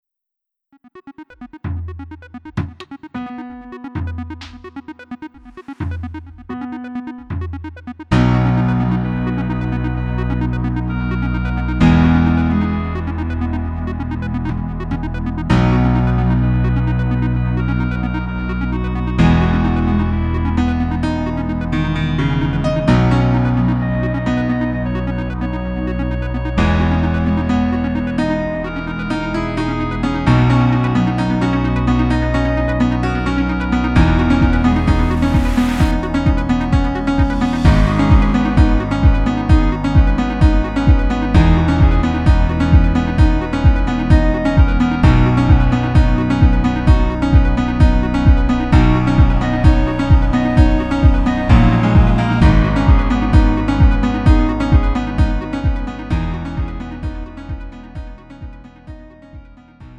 음정 -1키 3:37
장르 구분 Lite MR